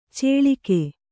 ♪ cēḷike